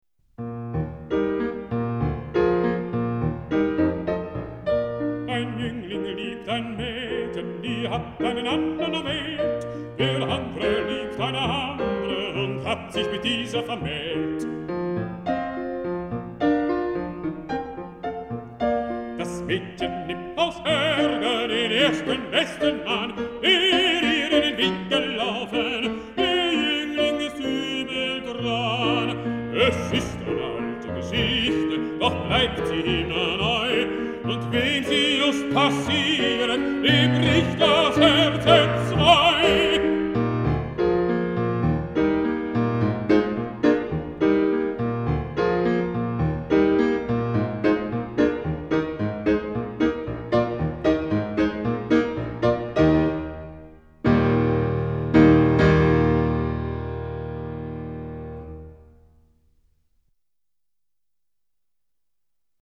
Música vocal
Música clásica